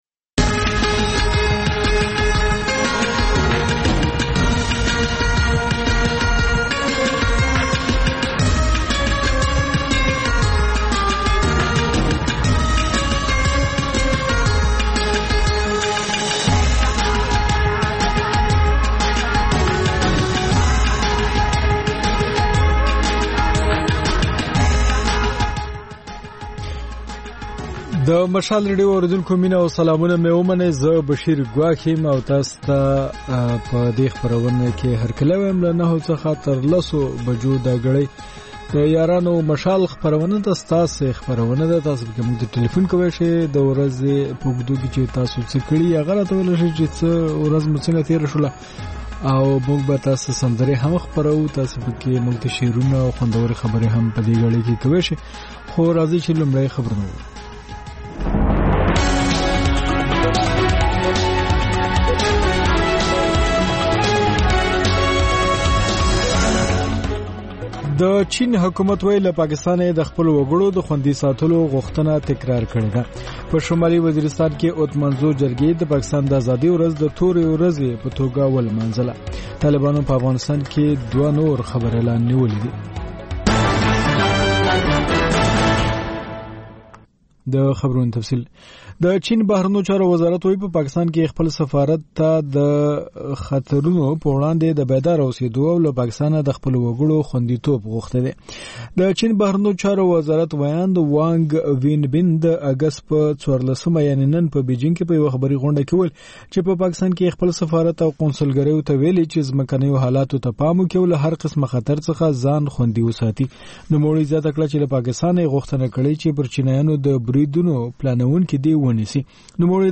د یارانو مشال په ژوندۍ خپرونه کې له اورېدونکو سره بنډار لرو او سندرې خپروو.